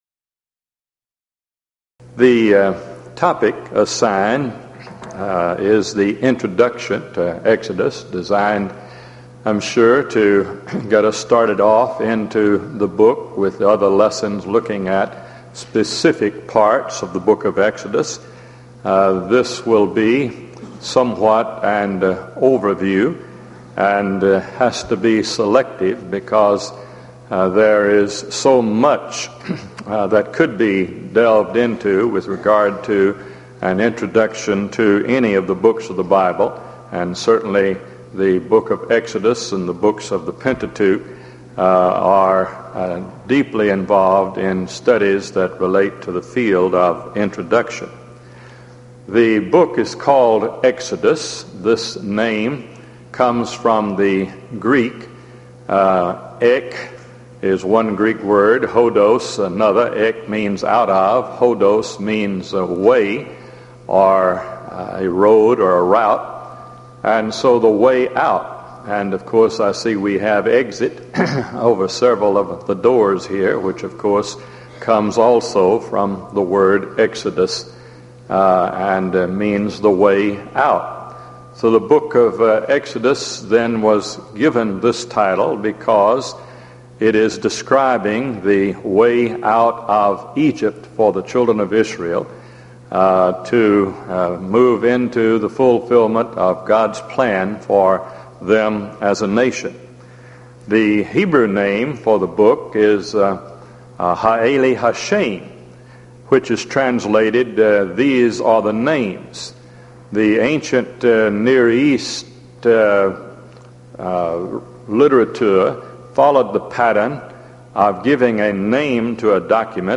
Event: 1997 East Tennessee School of Preaching Lectures
lecture